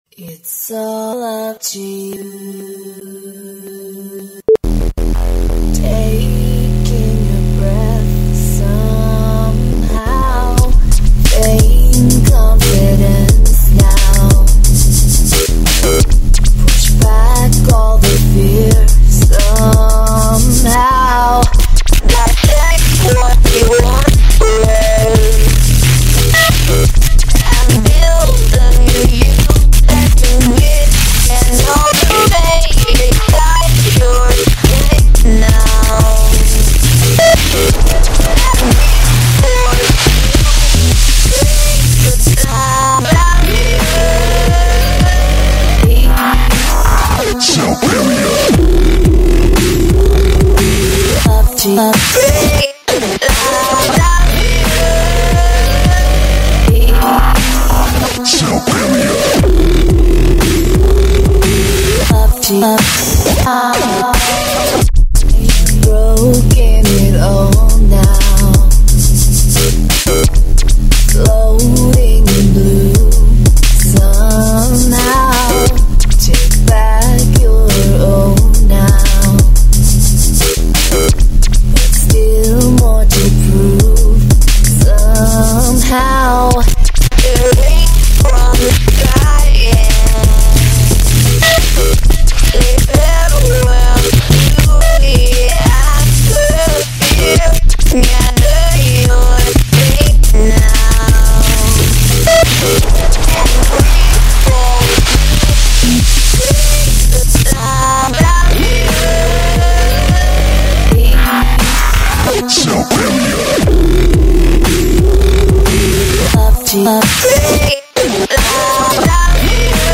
This is a short sweet inspired remix.